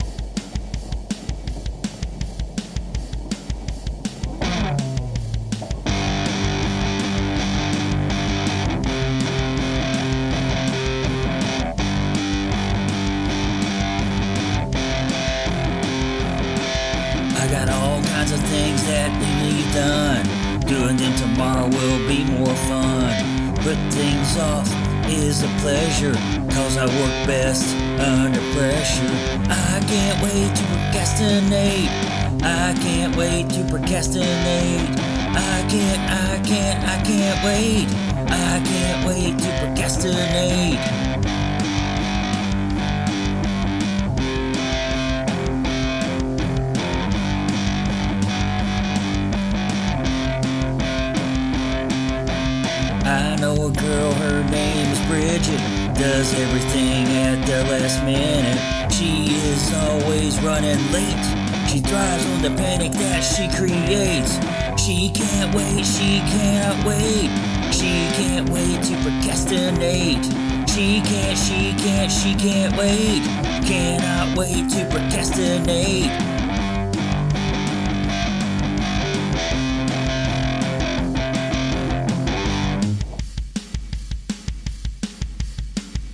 Very Ramones!